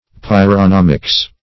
Pyronomics \Pyr`o*nom"ics\, n.